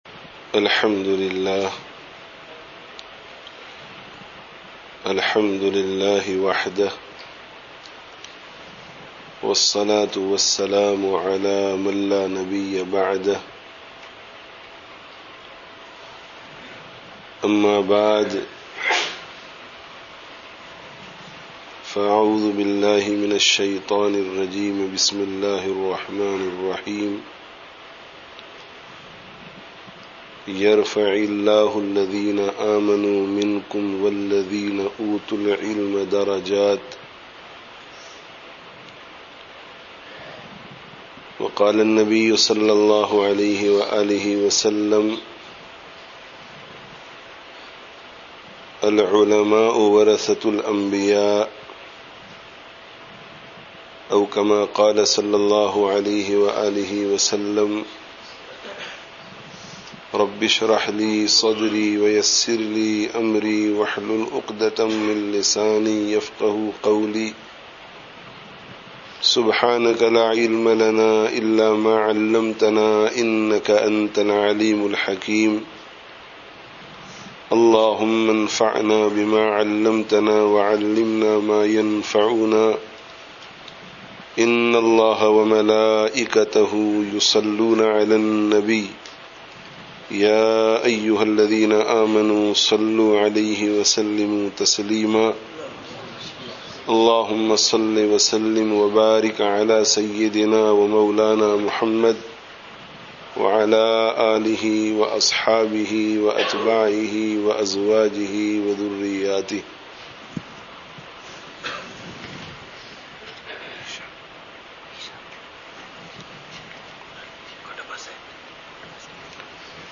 friday tazkiyah gathering